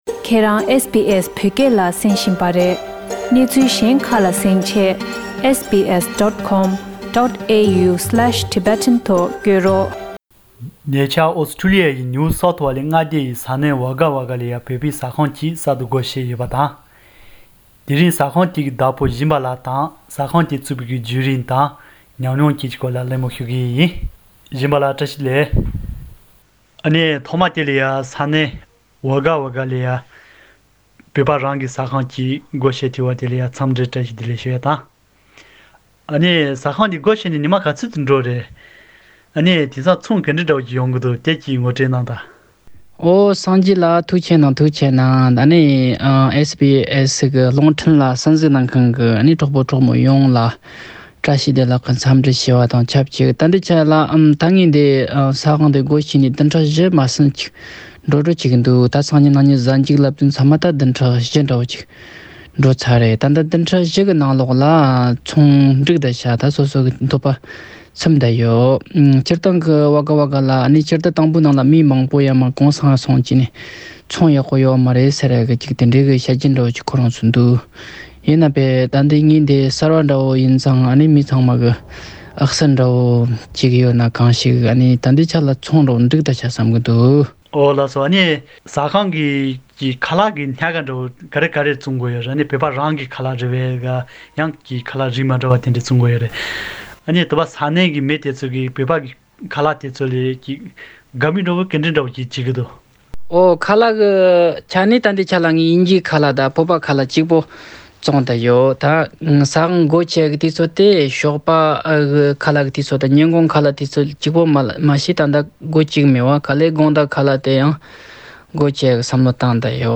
གླེང་མོལ་ཞུས་ཡོད།